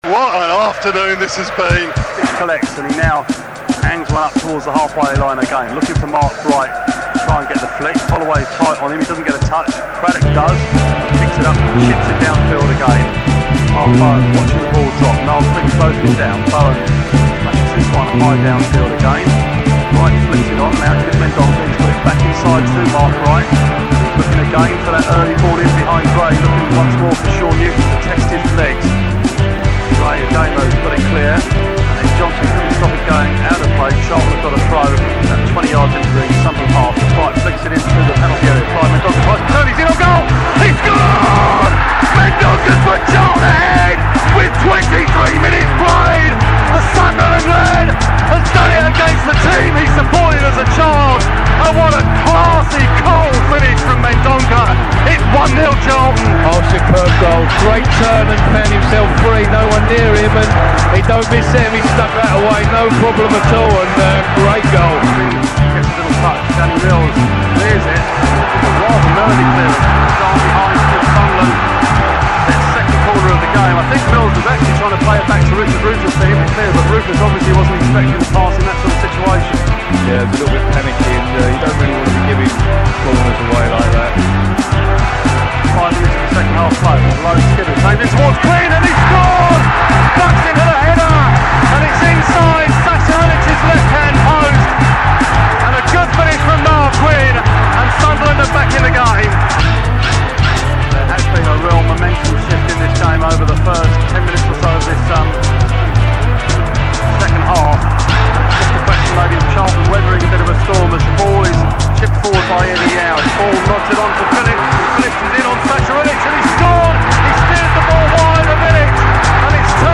Commentary from the 1998 Play Off Final at Wembley